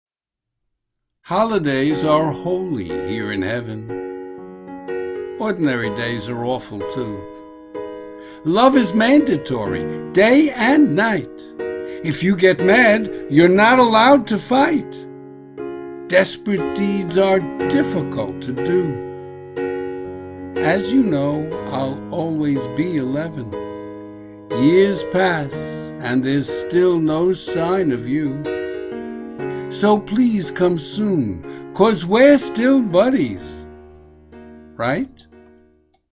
Video and Audio Music: